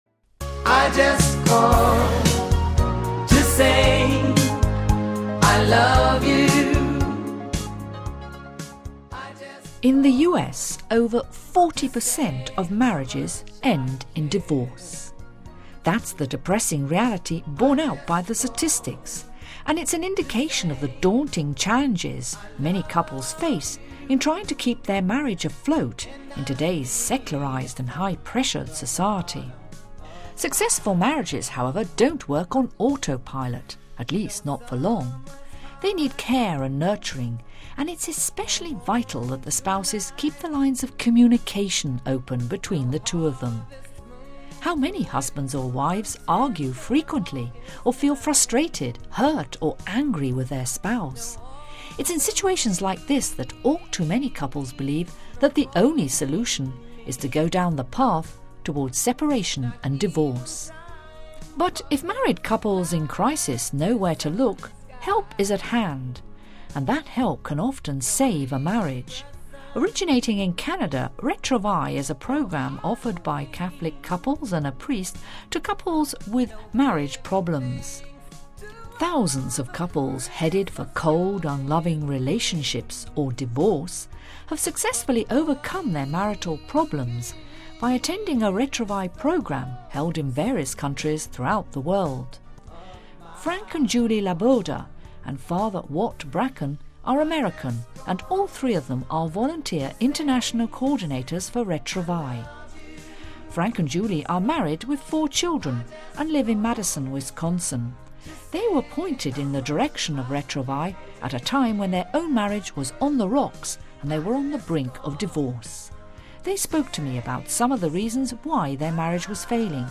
A husband and wife who were on the brink of divorce tell us about a Church-sponsored program that healed their union and that of thousands of other couples whose marriages were heading for the rocks....